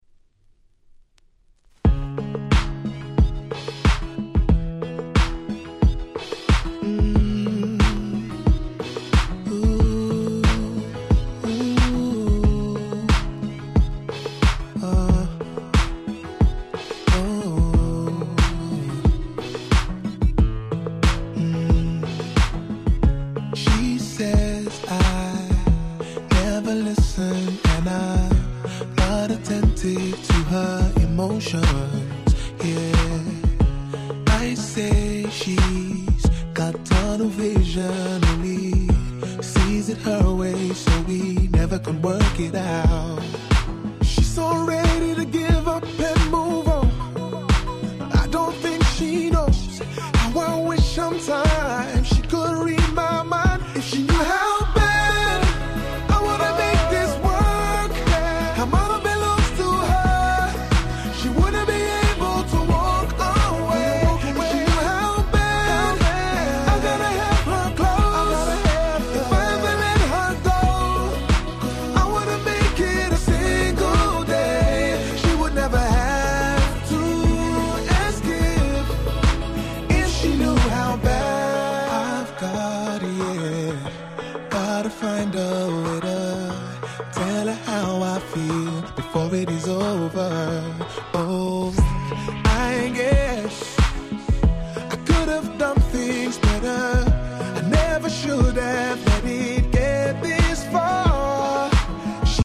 09' Nice R&B Compilation !!